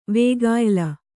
♪ vēgāyla